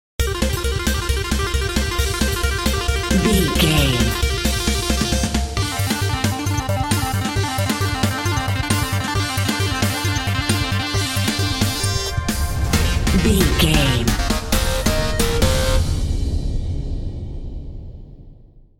Aeolian/Minor
Fast
intense
aggressive
synth
drums